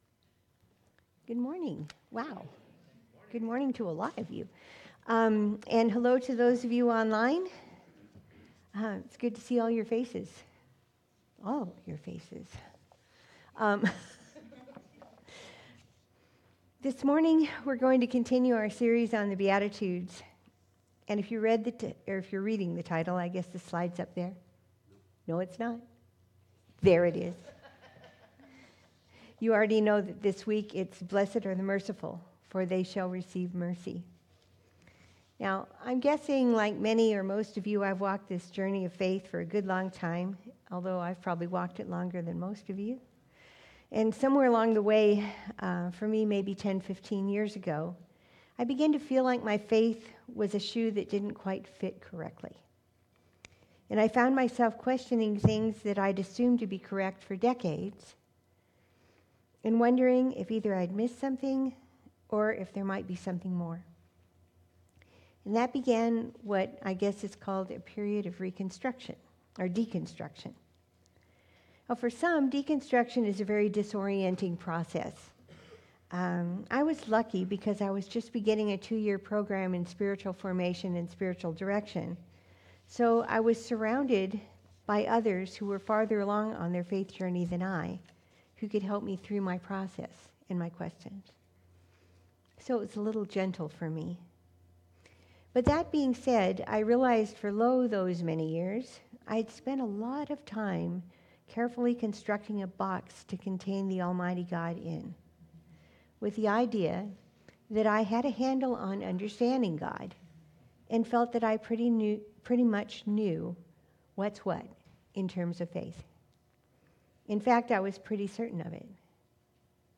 Sunday Messages and Bible teachings by the Teaching Team of Liminal Church of Ventura, a church in Southern California.